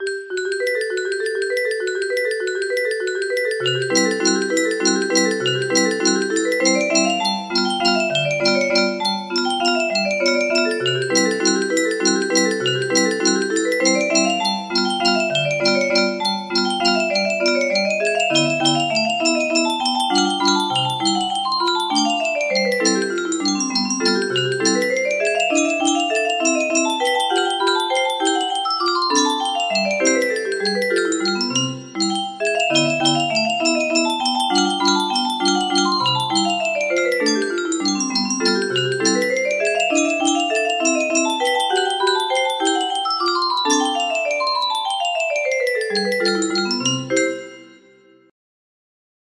Minute waltz - Chopin music box melody